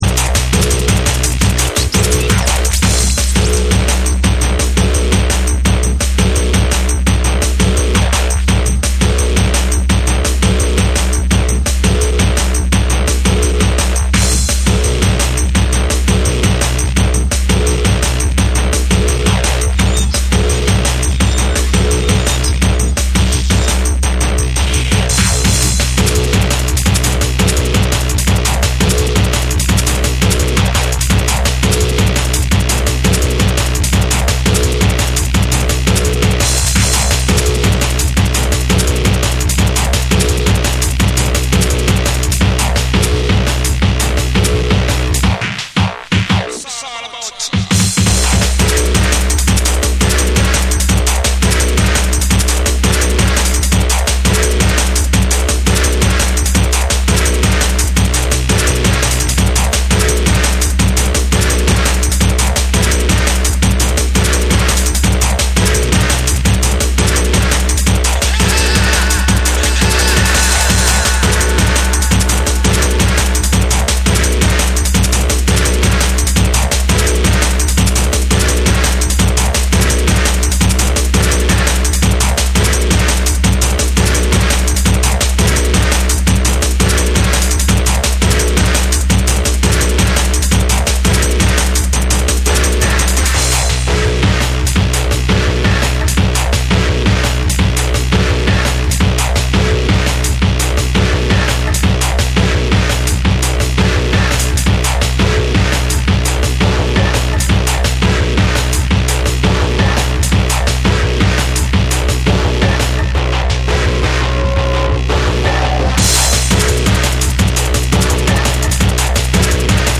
歪みを効かせたタイトなビートがダークかつクールに走る